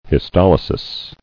[his·tol·y·sis]